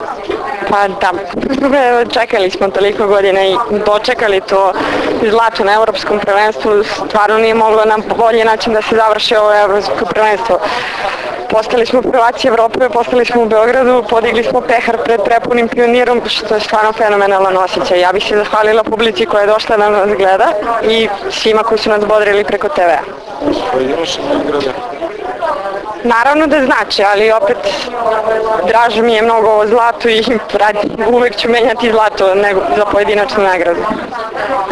IZJAVA SUZANE ĆEBIĆ